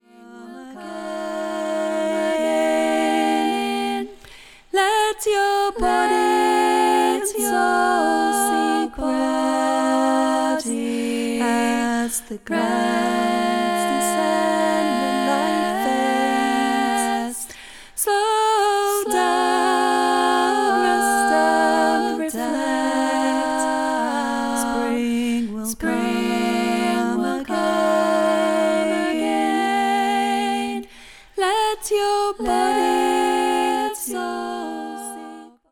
A song for group singing
Parts – 3